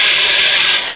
steam2.mp3